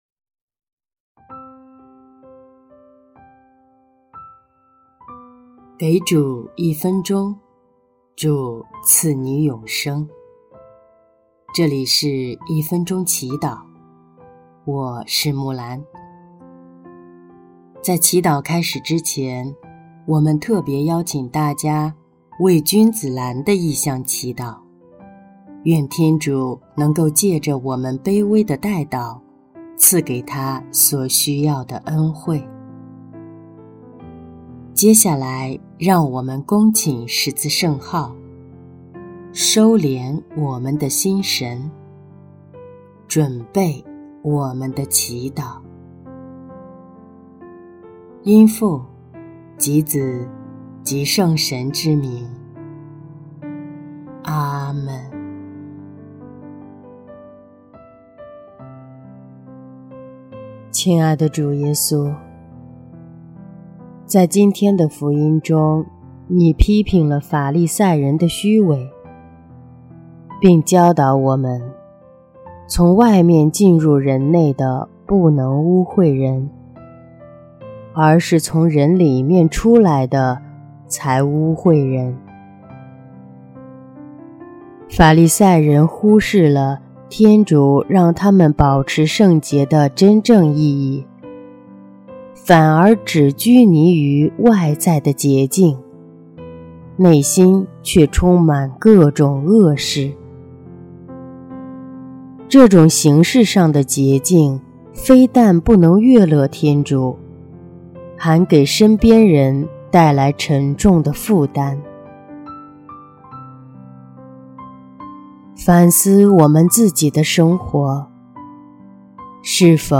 【一分钟祈祷】| 2月8日 主，请洁净我们，使我们言语行为都符合祢圣洁的诫命